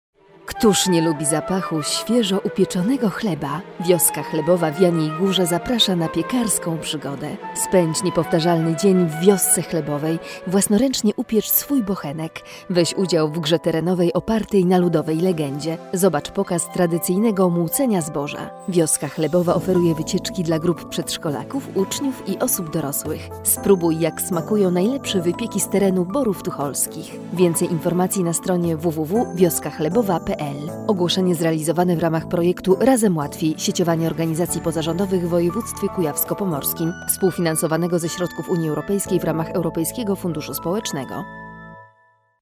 Nasza reklama w radio PiK